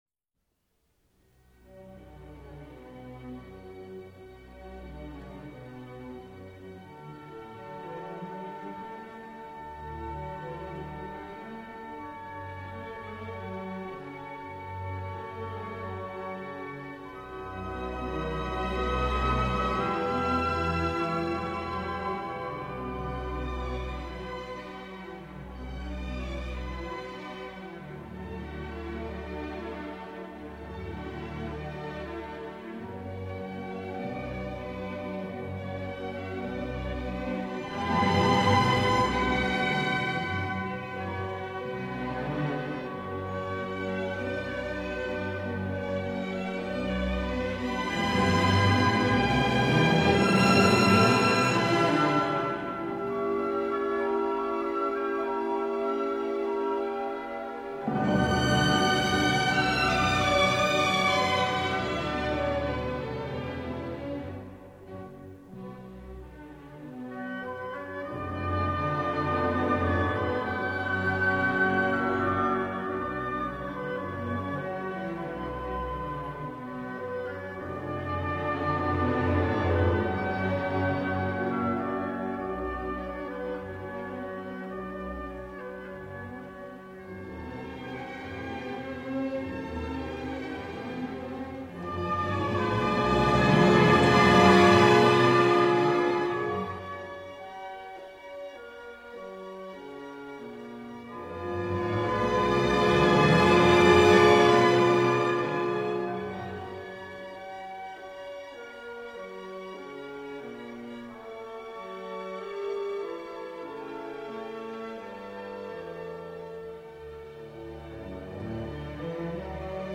• Felix Mendelssohn – Les Hébrides, ou la Grotte de Fingal. ***** Une superbe composition – en forme d’ouverture pour orchestre -, écrite en 1830 lors du voyage de Mendelssohn en Écosse.